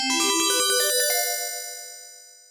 Jingle (I'll accept multiple different answers)